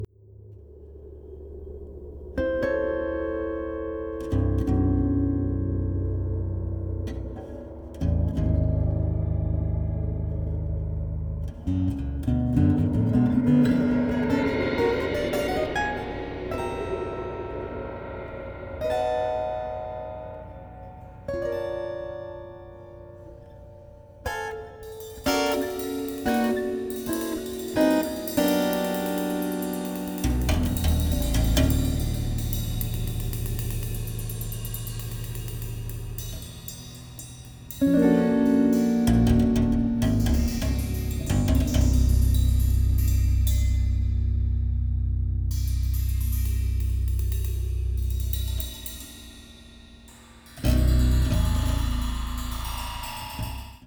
30-string Contra-Alto guitar
Percussion